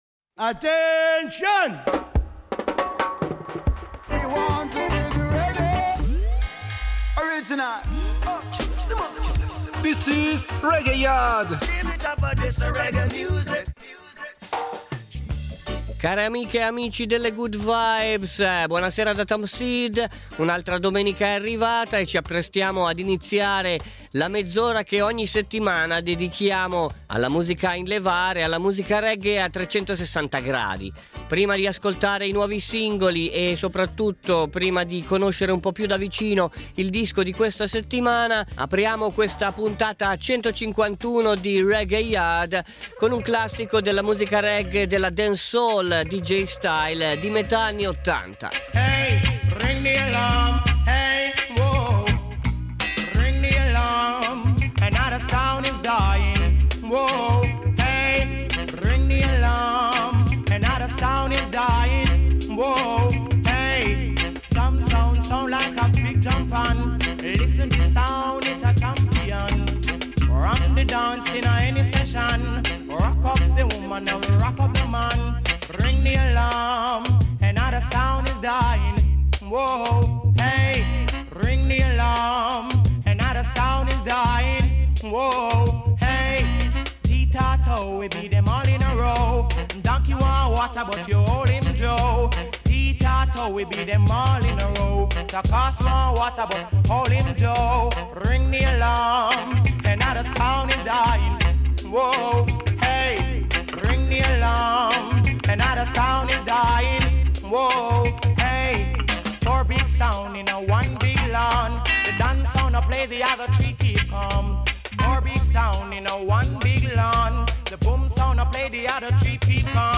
REGGAE / WORLD